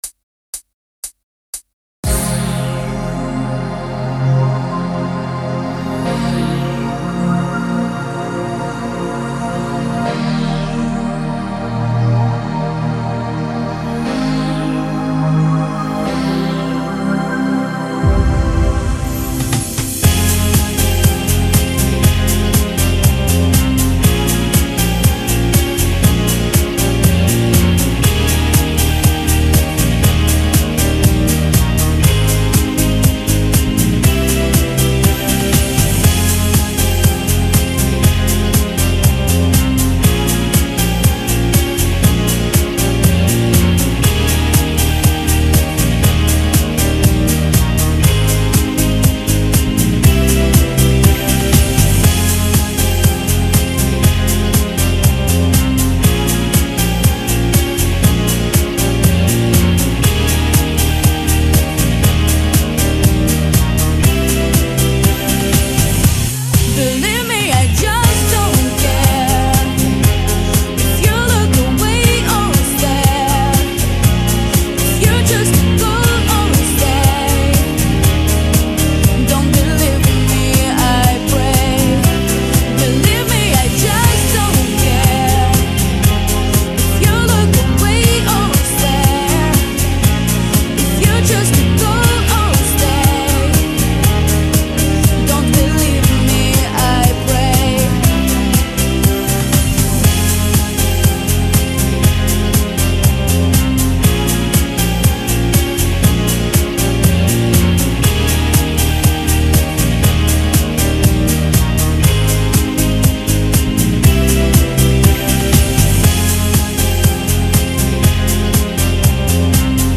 Качество:Ориг+Бэк